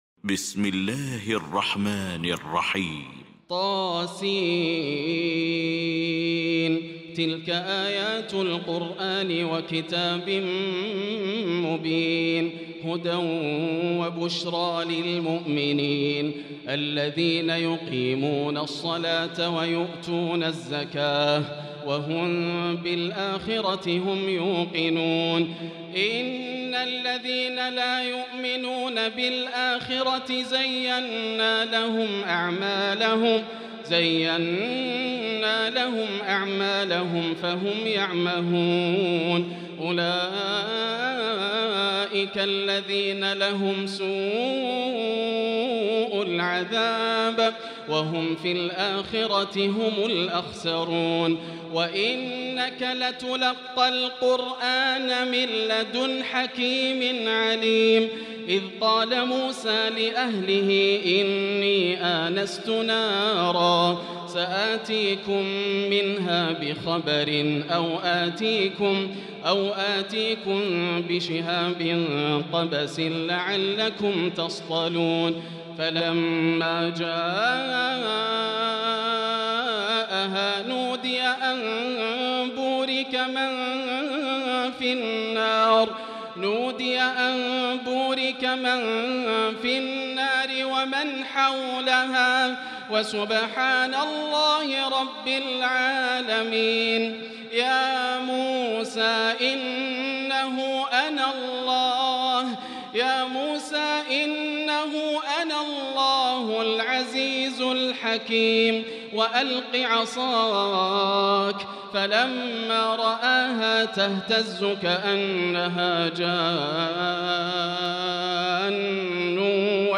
المكان: المسجد الحرام الشيخ: بدر التركي بدر التركي فضيلة الشيخ ياسر الدوسري النمل